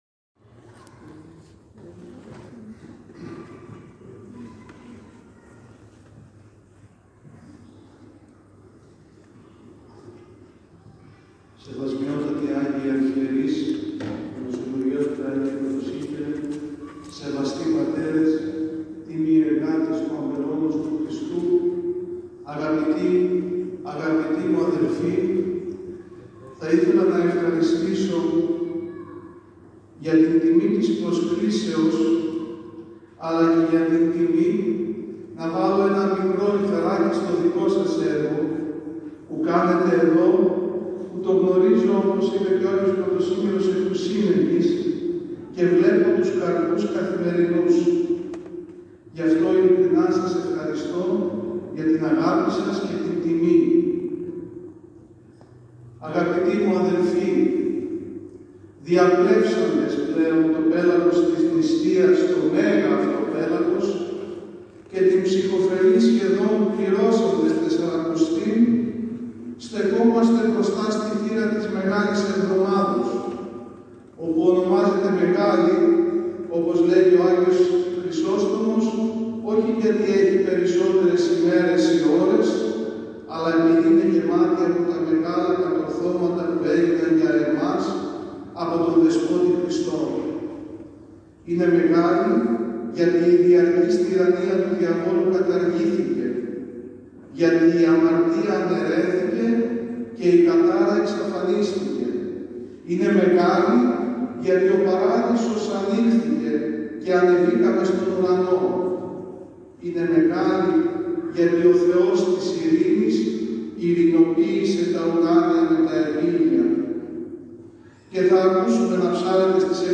ΟΜΙΛΙΑ
στὸν Ἱερὸ Μητροπολιτικὸ Ναὸ Ἁγίων Ἀναργύρων Νέας Ἰωνίας Ἀττικῆς